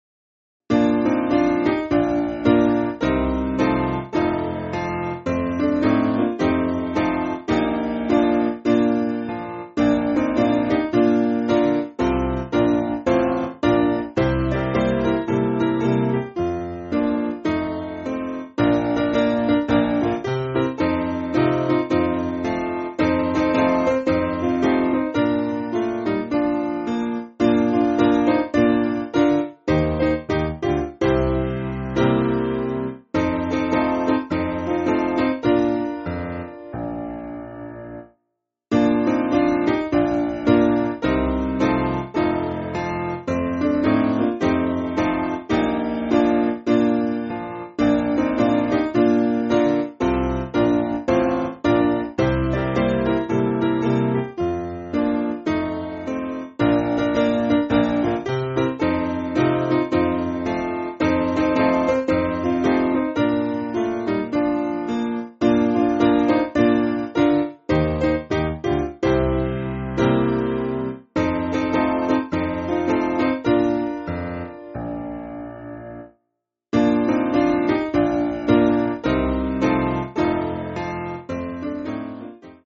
Simple Piano
(CM)   4/Bb